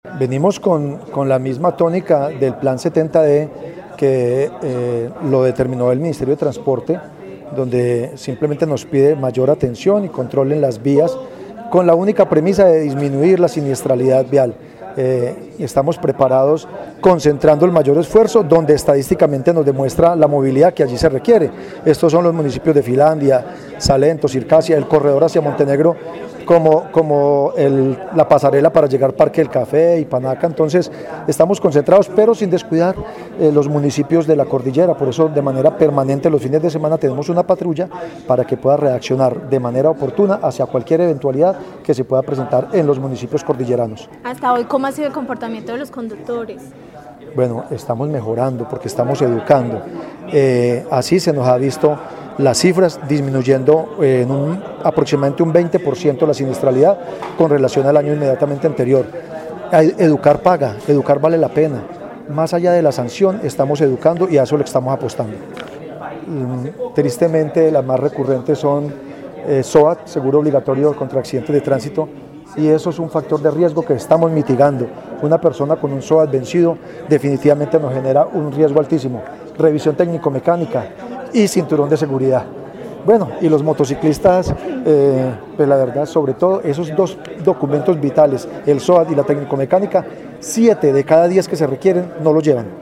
Director IDTQ, Uriel Enoc Ortiz